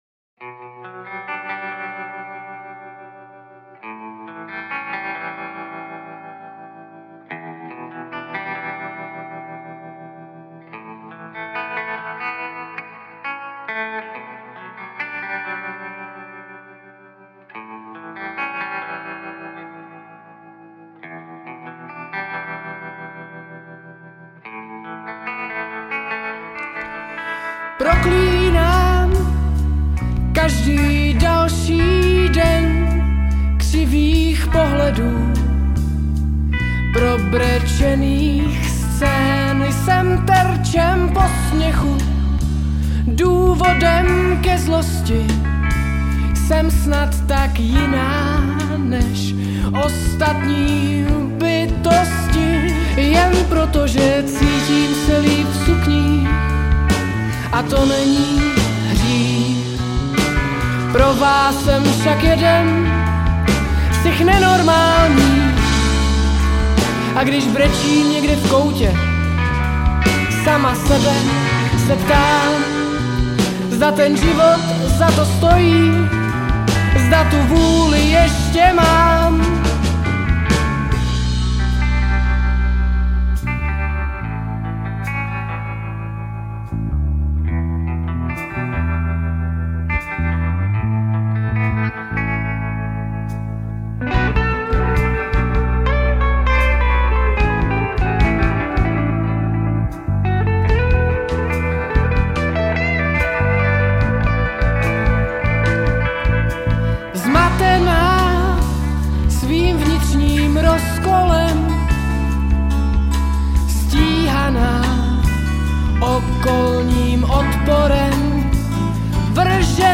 Žánr: Indie/Alternativa